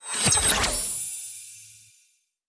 win_line_new.wav